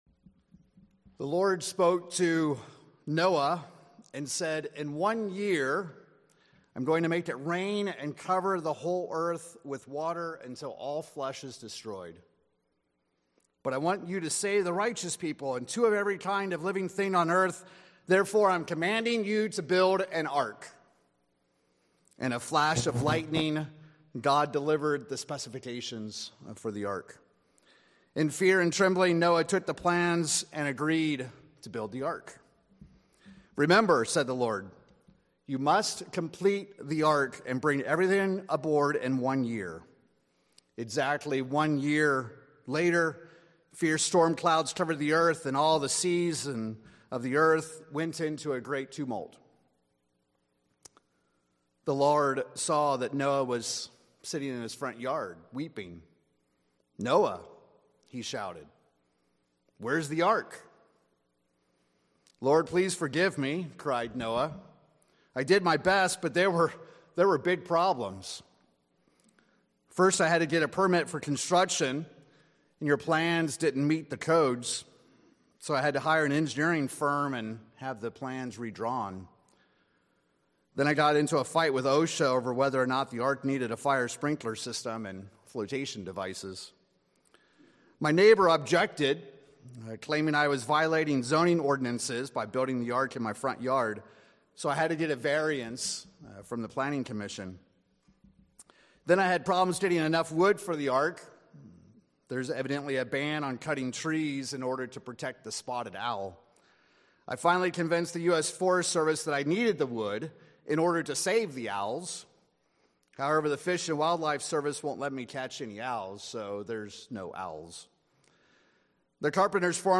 In this sermon, we examine the life of Noah and the ark that he built as representation of four characteristics that we each should have in our own spiritual ark.